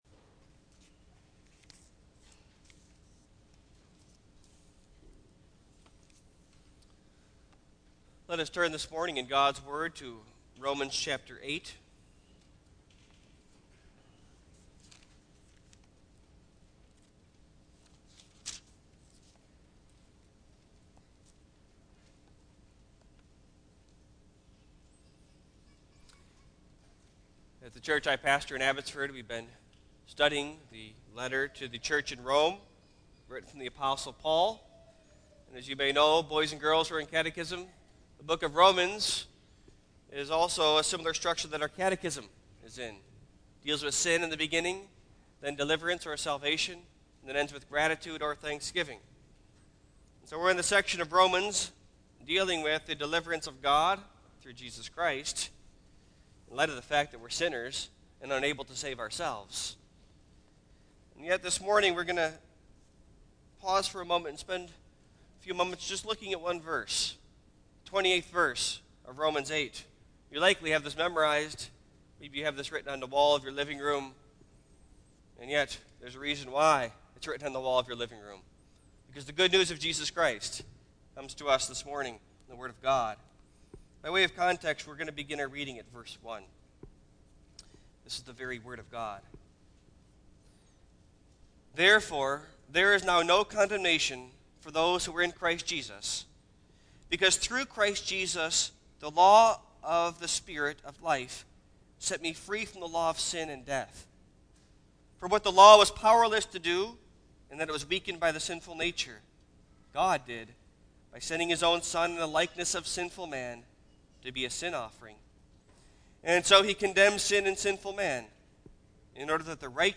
Single Sermons Passage: Romans 8:1-30 %todo_render% « What’s the Use in Praying?